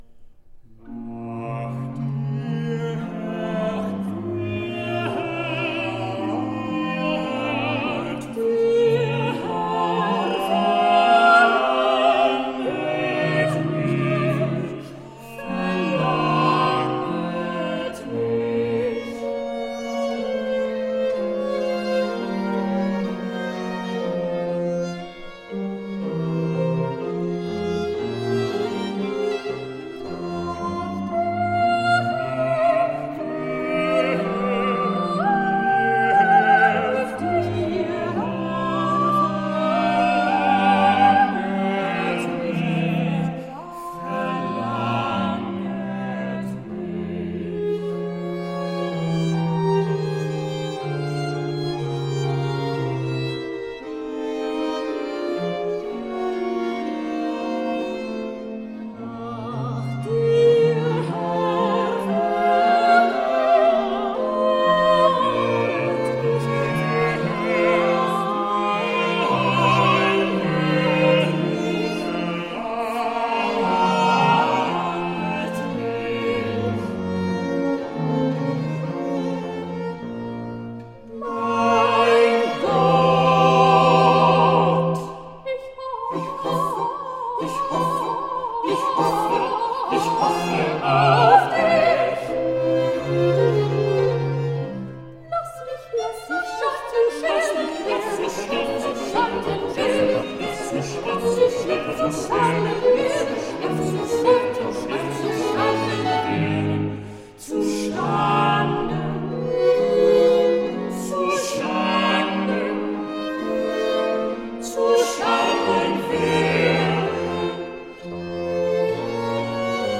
Baroque instrumental and vocal gems.
organ / harpsichord